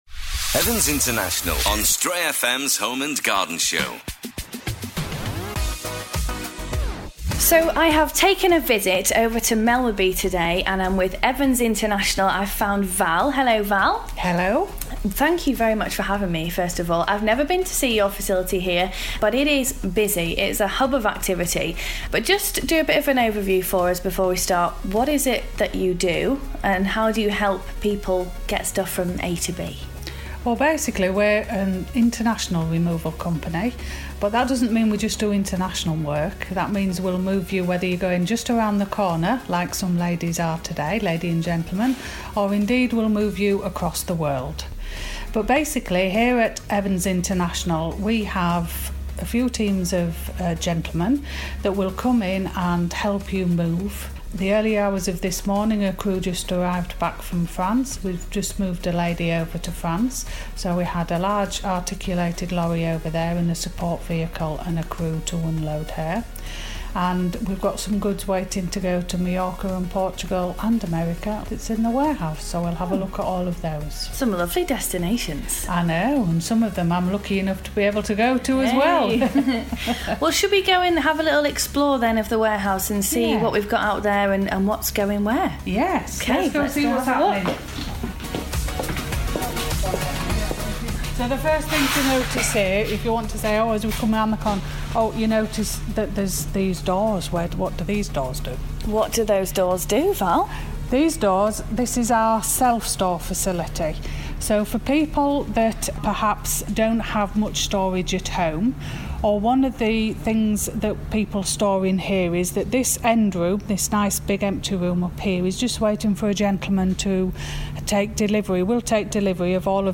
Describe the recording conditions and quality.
From moving 22ft long yachts to range rovers, from storing shoes to keeping house plants alive, Evans International can handle pretty much any removal issue. We took a visit to their warehouse in Melmerby, Ripon to see what treasures awaited...